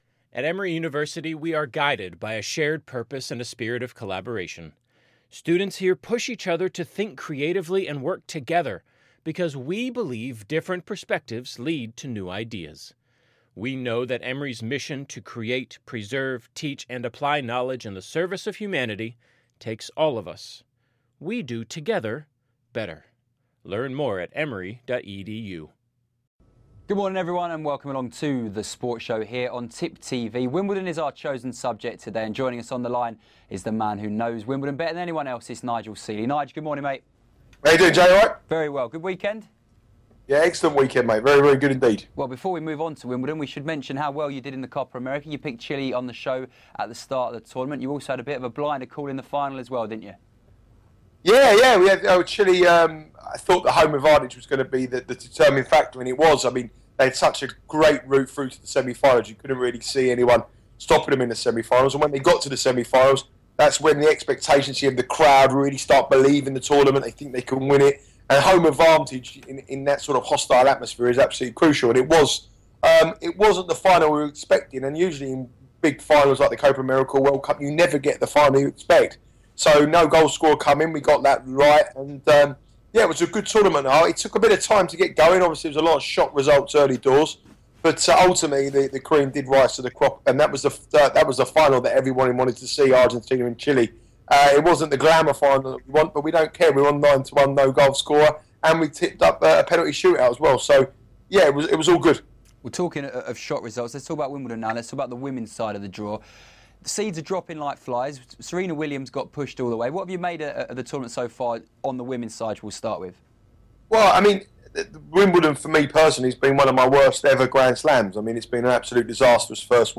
With some big games coming up today and the competition starting to reach a close; what do the boys in the studio make of the tournament so far?